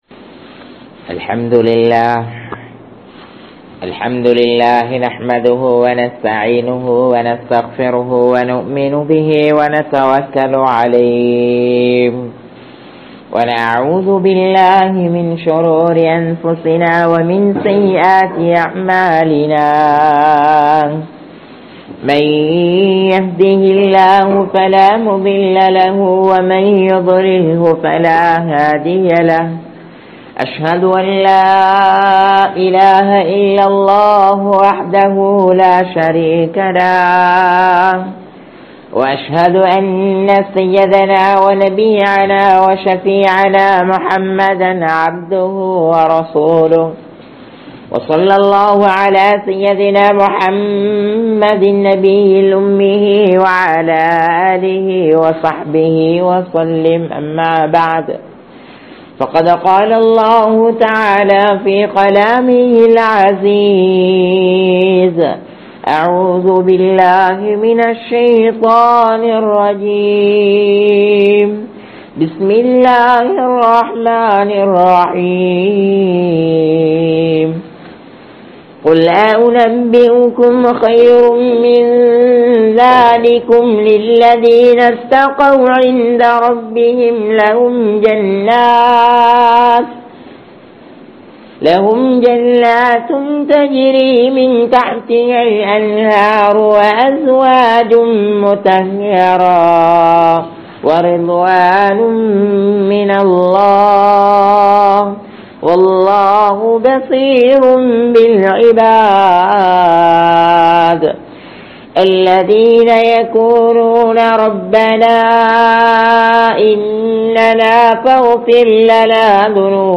Allah`vin Vaakkuruthihal (அல்லாஹ்வின் வாக்குறுதிகள்) | Audio Bayans | All Ceylon Muslim Youth Community | Addalaichenai